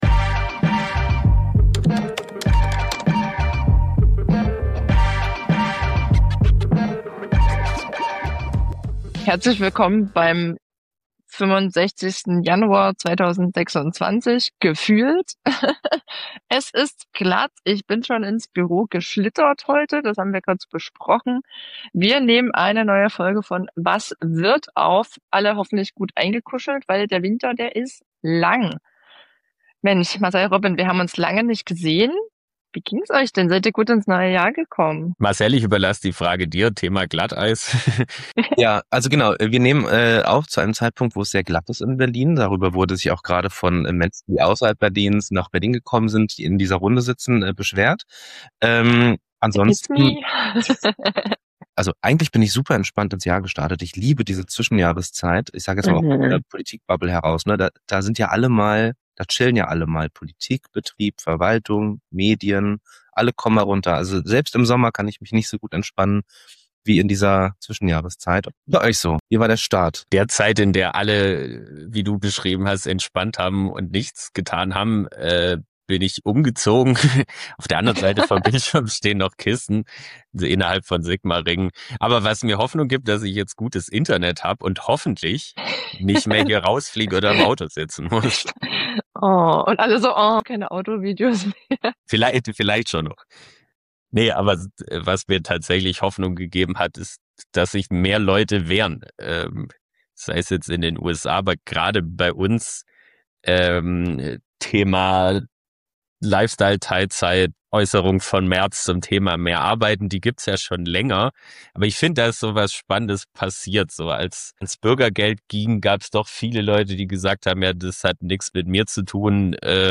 Inmitten dieser herausfordernden, politischen bewegenden Zeiten wollen wir zu Dritt und auch mit spannenden Gästen über die großen Fragen sprechen: Wie geht’s weiter?